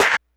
snare01.wav